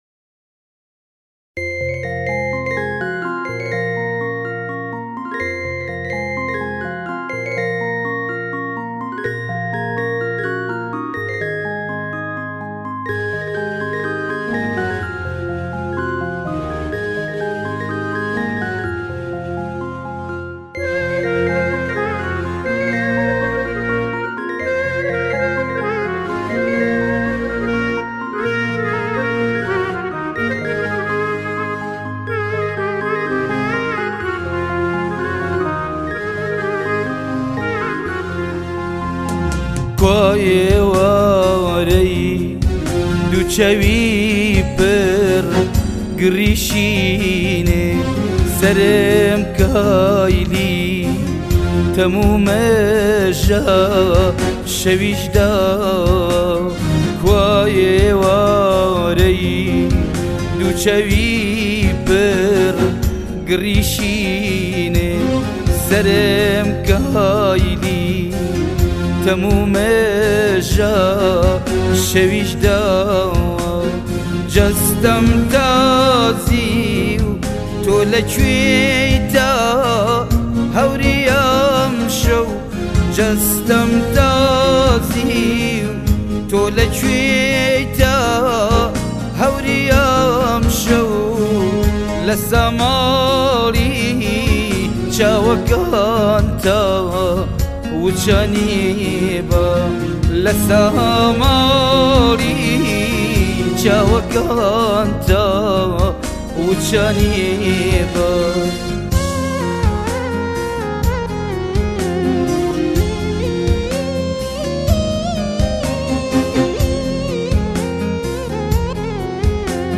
آهنگ های جدید کردی